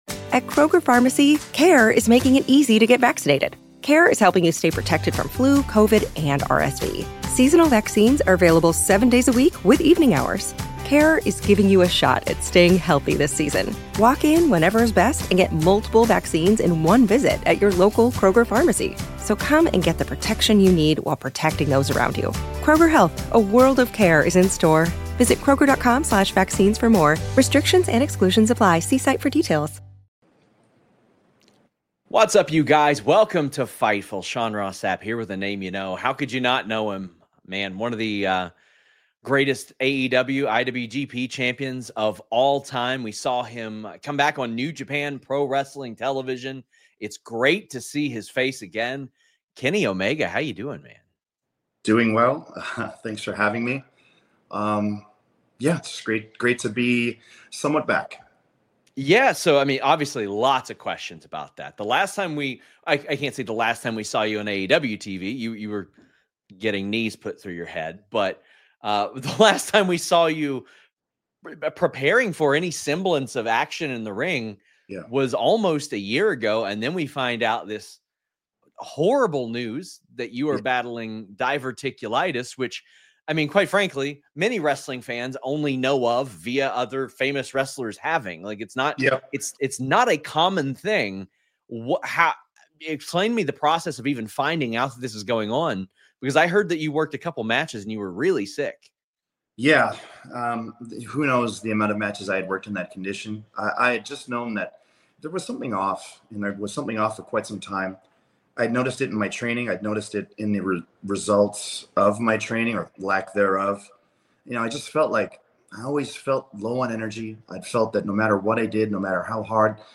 Shoot Interviews